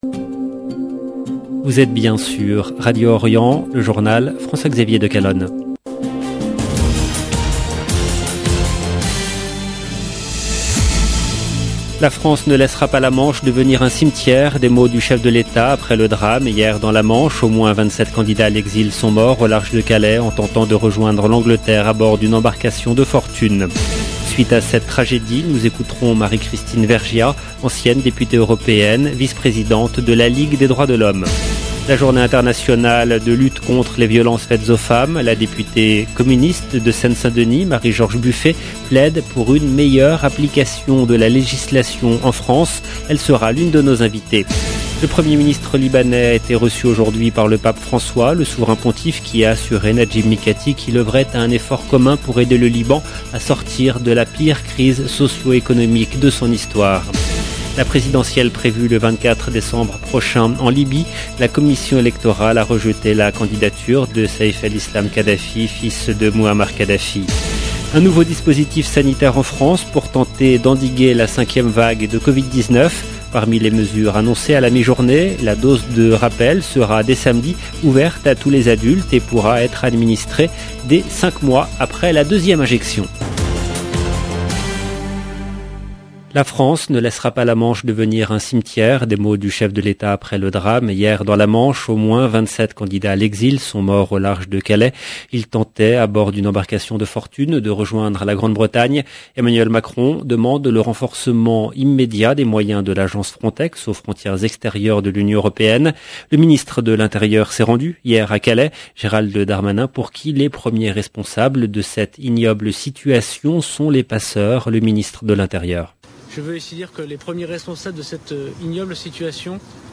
LB JOURNAL EN LANGUE FRANÇAISE
La député communiste de Seine Saint Denis Marie George Buffet plaide pour une meilleure application de la législation en France.